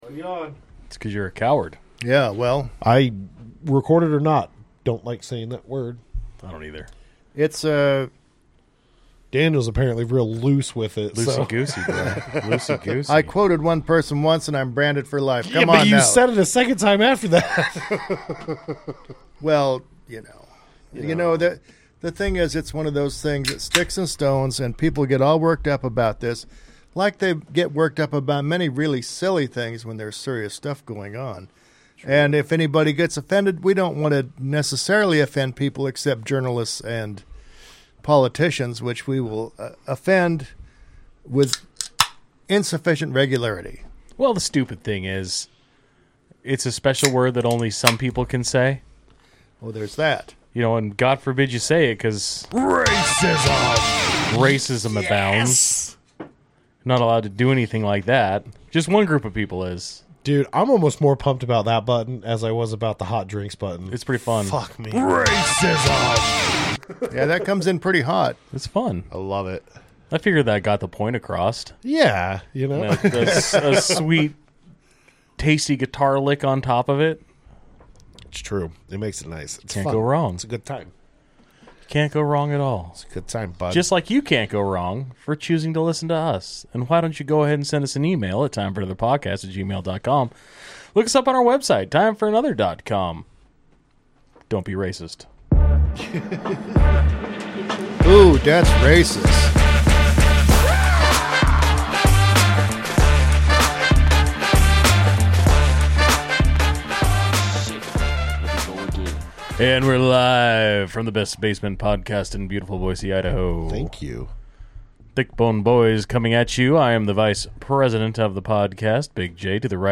Play Rate Listened List Bookmark Get this podcast via API From The Podcast 6 Three guys talking about everything from current events to politics to food to wild stories we find on the internet and anything in between. The kind of conversations you have sitting at a bar or hanging with your buddies in the garage having a few cold ones (which we encourage greatly).